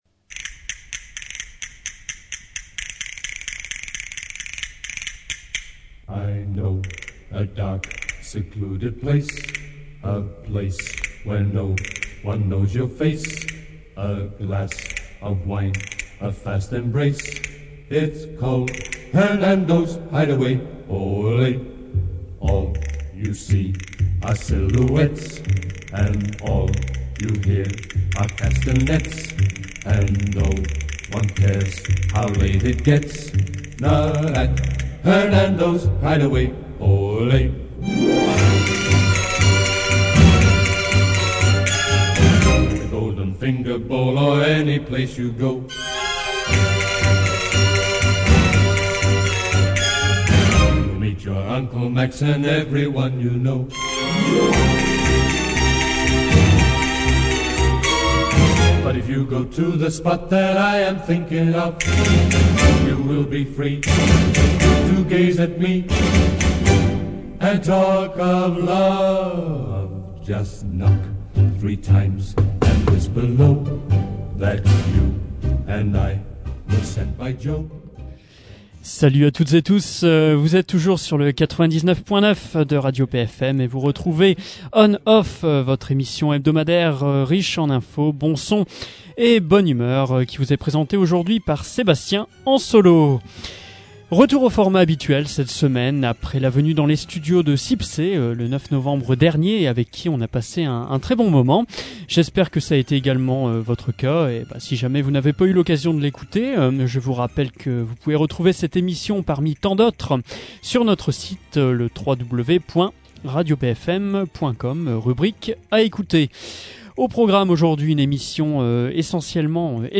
Une mission particulirement electro...